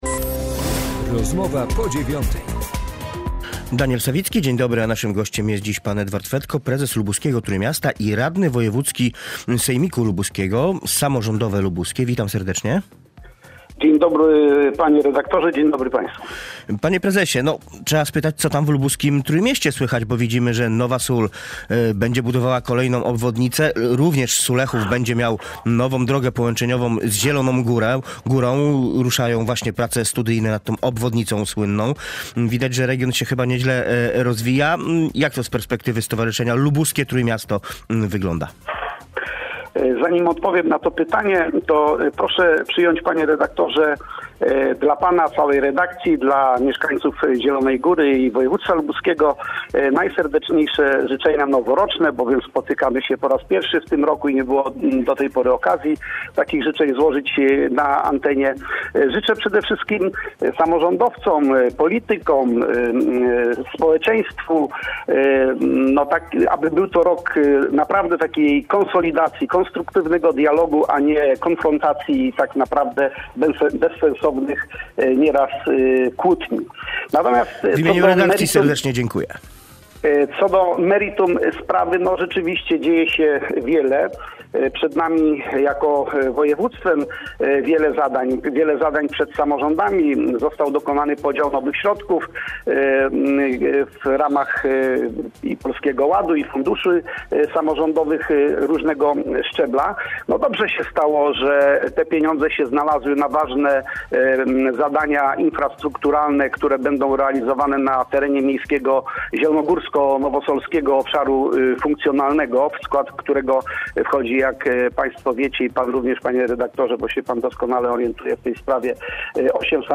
Z radnym wojewódzkim z klubu Samorządowe Lubuskie, prezesem Lubuskiego Trójmiasta rozmawia